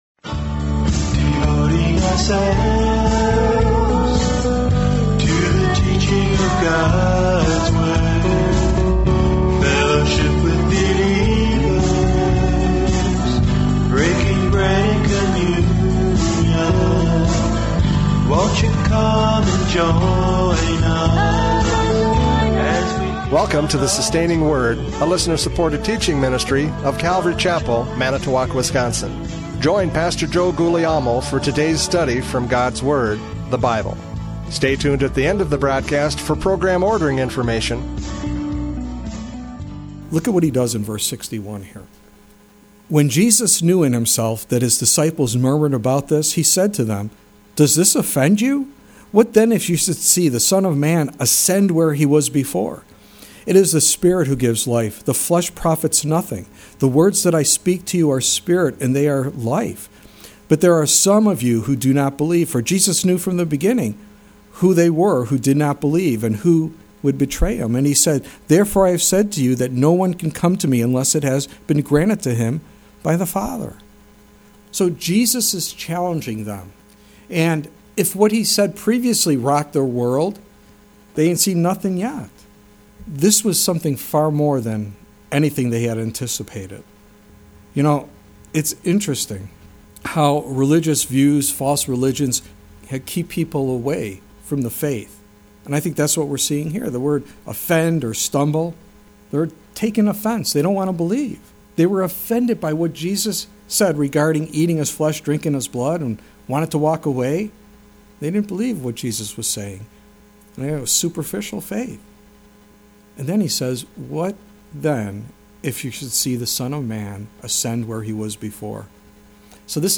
John 6:60-71 Service Type: Radio Programs « John 6:60-71 True and False Disciples!